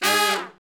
Index of /90_sSampleCDs/Roland LCDP06 Brass Sections/BRS_Section FX/BRS_R&R Falls